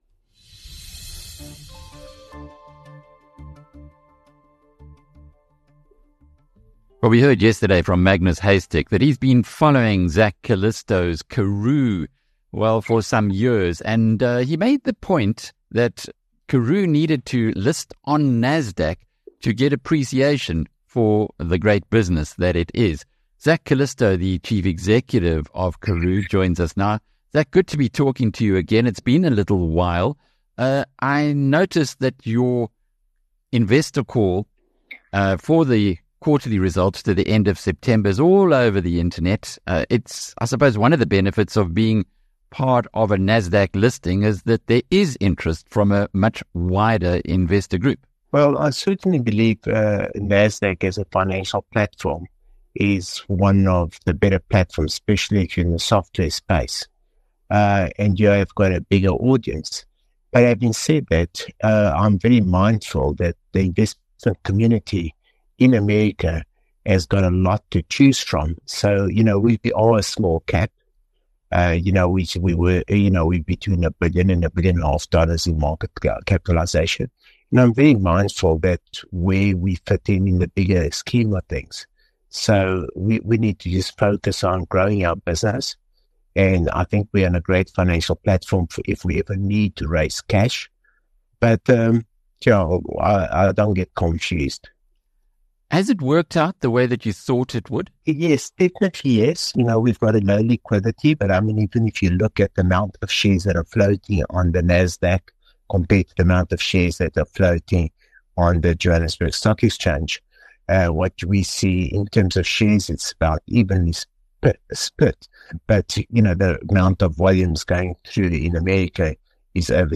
In an insightful interview